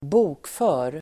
Uttal: [²b'o:kfö:r]